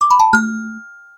09109 kalimba game bonus
alert bonus ding game kalimba mallet notification sound effect free sound royalty free Sound Effects